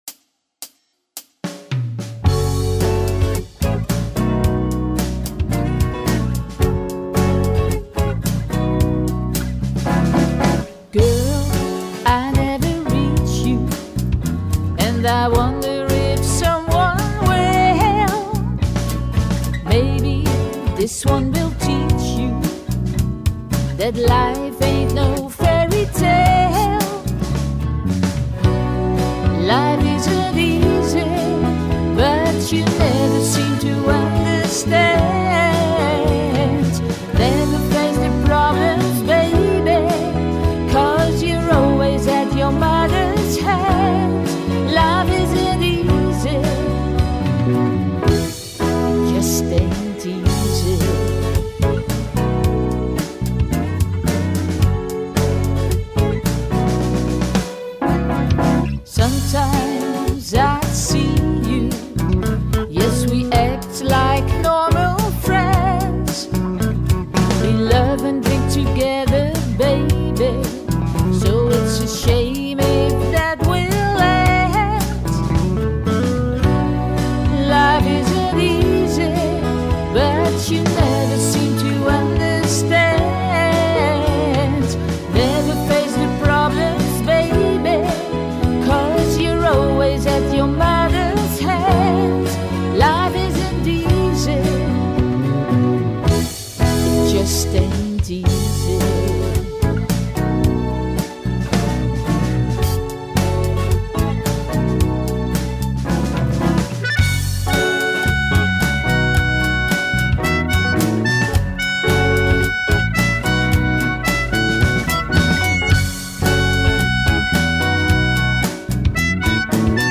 Hieronder de demo: